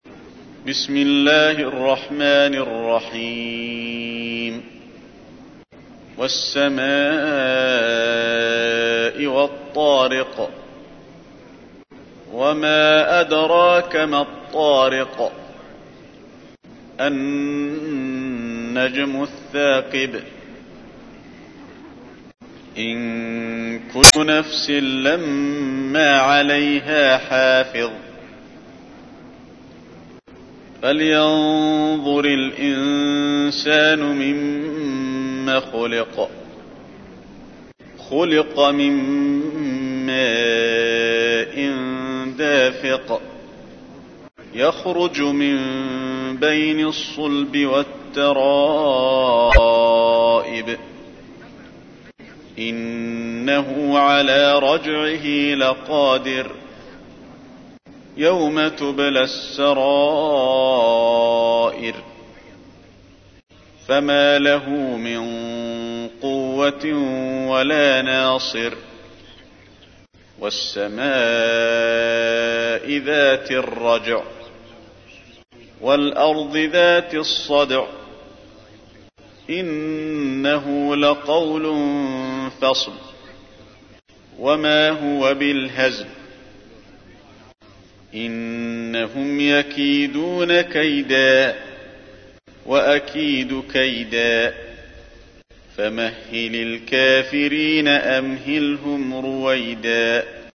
تحميل : 86. سورة الطارق / القارئ علي الحذيفي / القرآن الكريم / موقع يا حسين